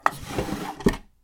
桐タンス小引出し開
op_chest_drawer2.mp3